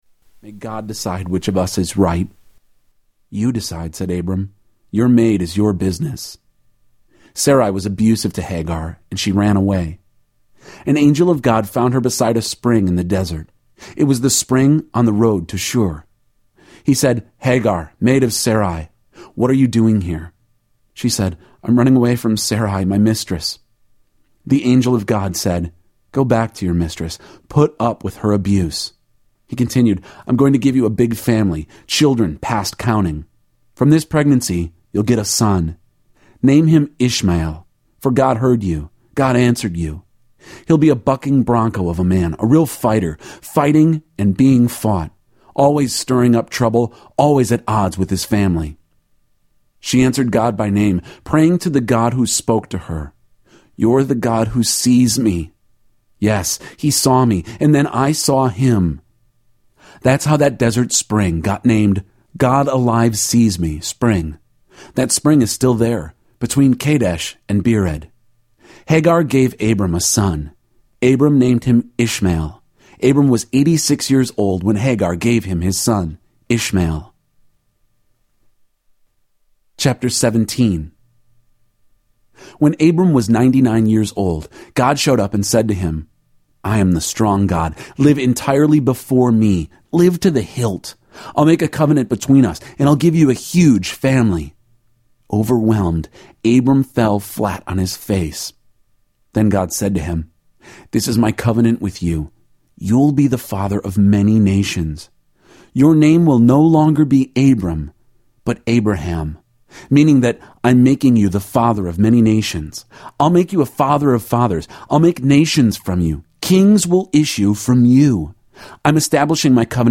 Tags: Christian Books Audio books Christian Audio books Media